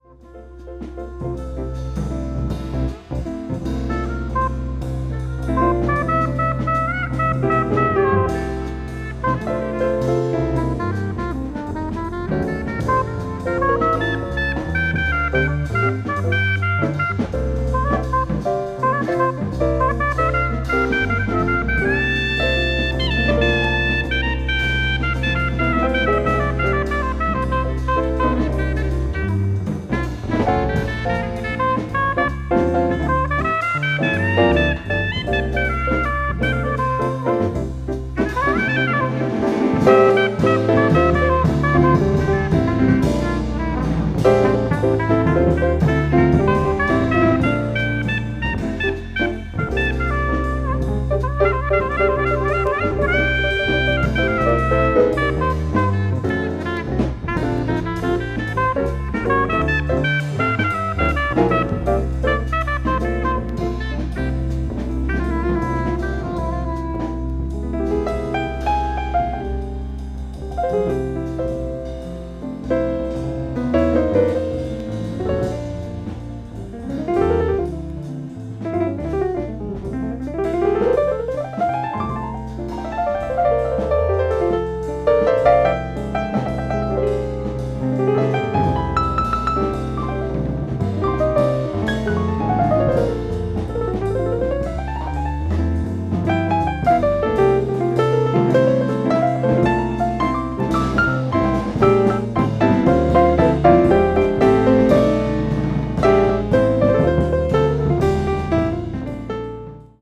bossa jazz   modal jazz   mood jazz   smooth jazz